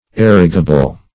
Search Result for " erigible" : The Collaborative International Dictionary of English v.0.48: Erigible \Er"i*gi*ble\ ([e^]r"[i^]*j[i^]*b'l), a. [See Erect .] Capable of being erected.